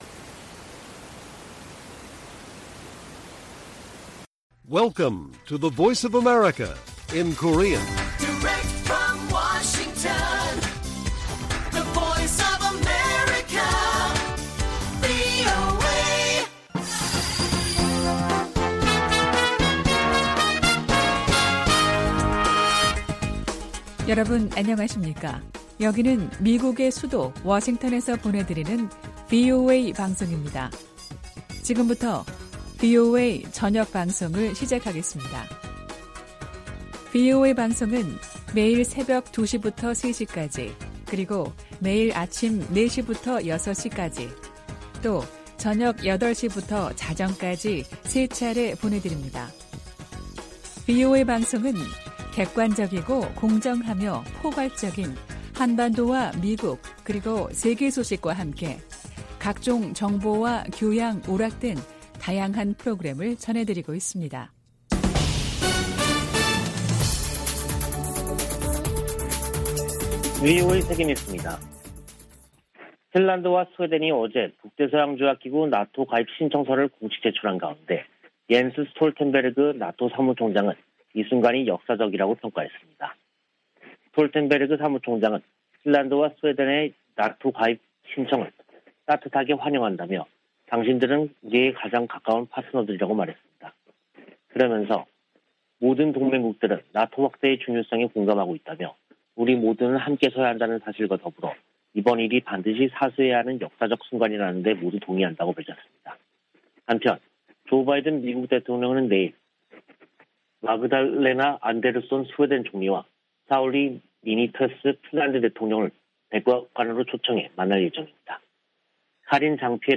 VOA 한국어 간판 뉴스 프로그램 '뉴스 투데이', 2022년 5월 18일 1부 방송입니다. 오는 21일 서울에서 열리는 미한 정상회담에서 북한 핵 위협에 대한 실효적인 확장억제력 강화 방안이 핵심적으로 다뤄질 것이라고 한국 대통령실이 밝혔습니다. 미 국무부는 북한이 코로나바이러스 감염증 확산세 속에서도 핵실험 의지를 꺾지 않을 것이라고 내다봤습니다. 미 의회에 발의된 북한 인권법 연장 법안에 코로나 관련 지원 기조가 처음으로 명기됐습니다.